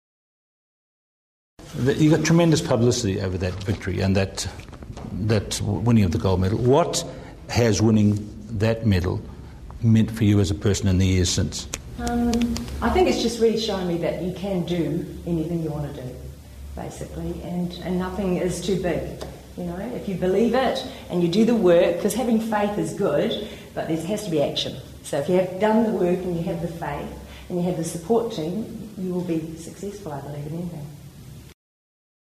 These sound tracks were produced from videos made at each of the Peak Performance Seminars.
Video and audio quality is not good for all events due to local venue conditions.